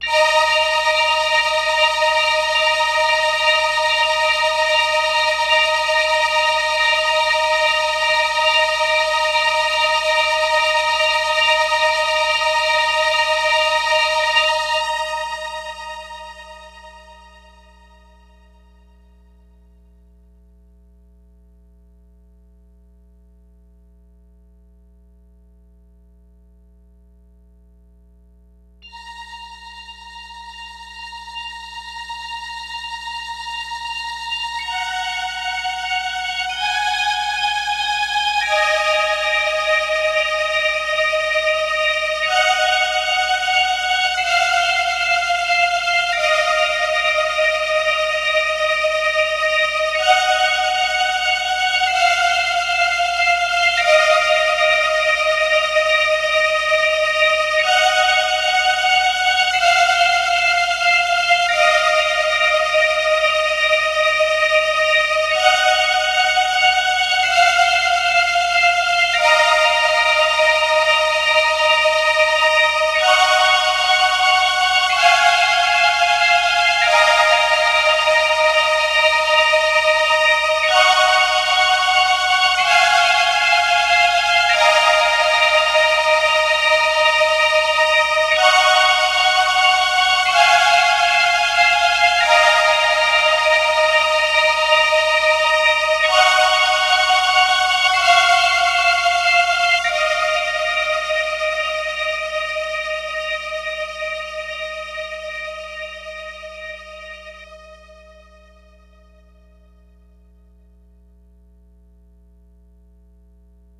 Strings.wav